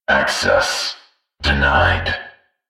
voxAccessDenied.ogg